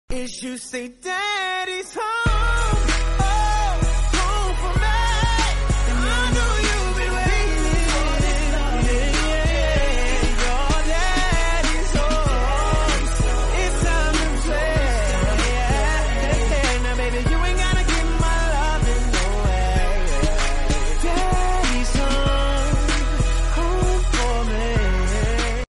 Dishoom Efecto de Sonido Descargar
Dishoom Botón de Sonido